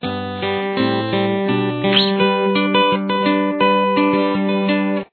This fingerstyle classic is a great song to learn.